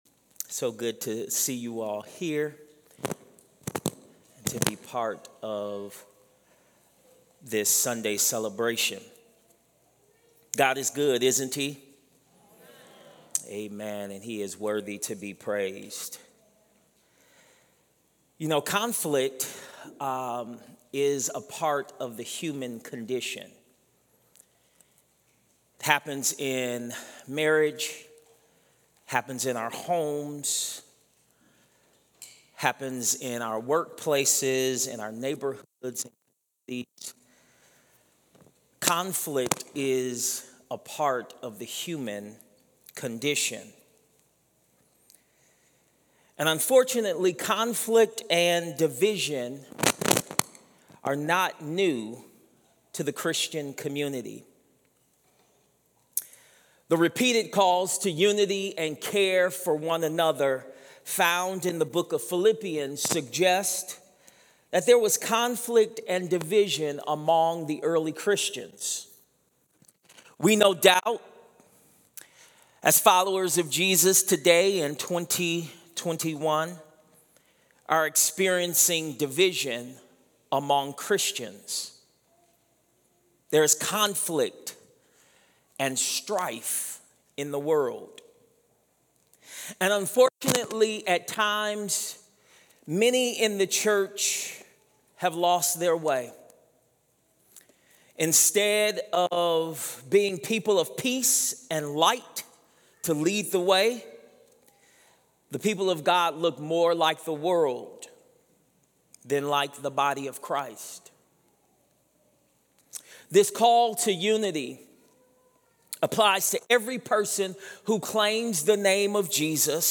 Sermons | Tabernacle Community Church